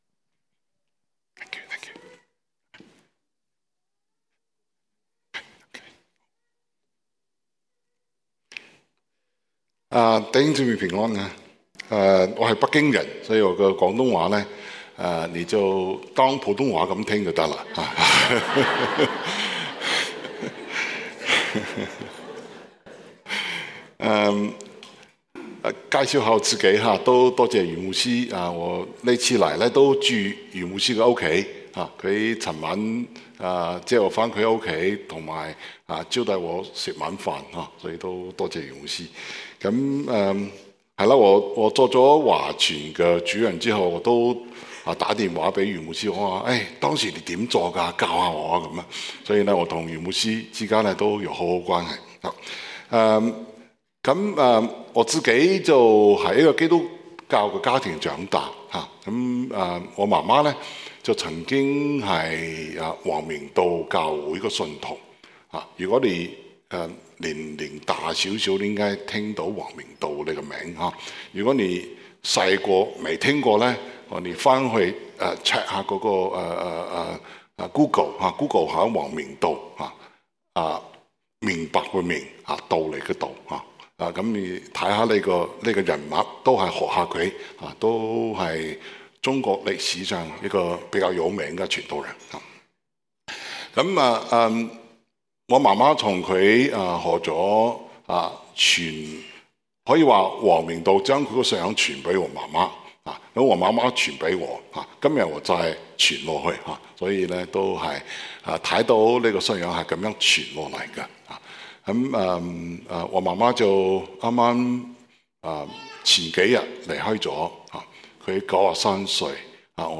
Sermon – 第 21 頁 – 澳亞基督教會 Austral-Asian Christian Church